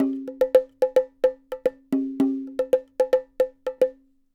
Bongo 13.wav